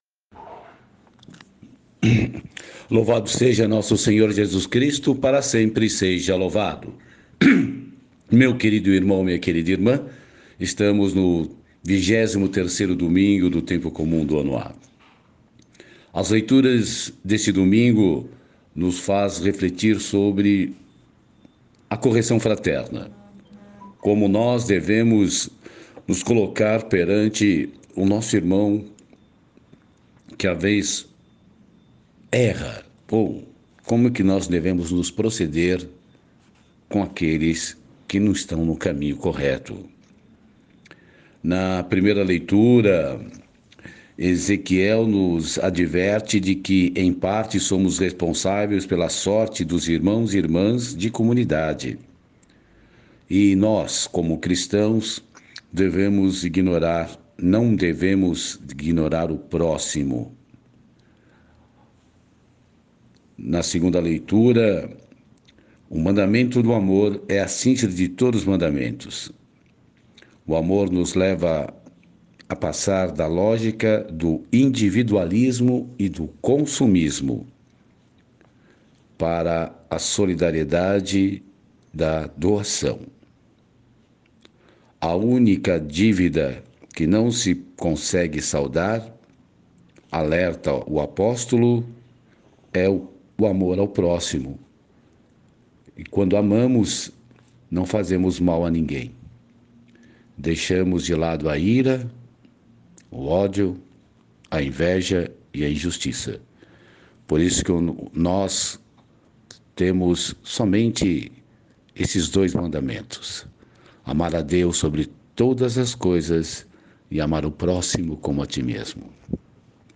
Reflexão e Meditação do 23 Domingo do Tempo Comum. Ano A